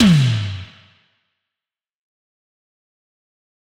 Tom_G2.wav